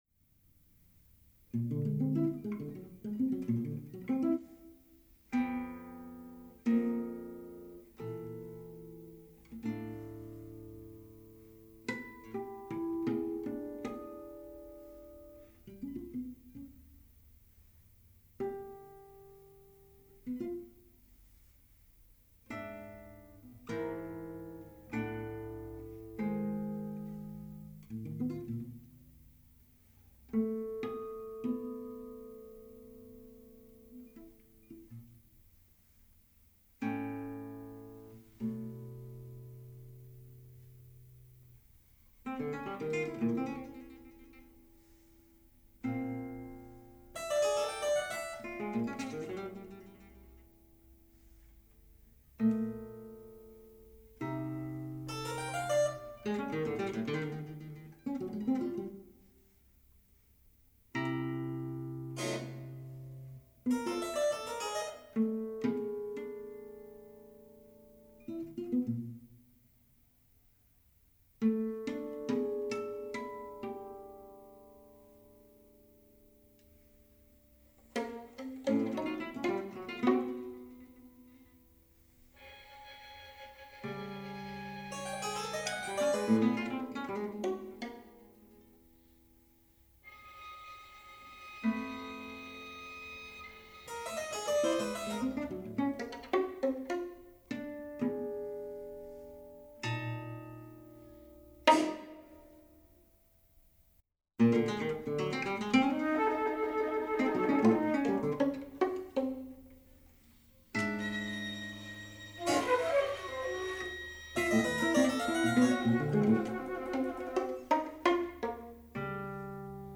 Musica per balletto
Per voce recitante e canto
flauto e ottavino
arpa
oboe
violino
pianoforte e clavicembalo
chitarra e percussioni